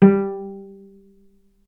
vc_pz-G3-ff.AIF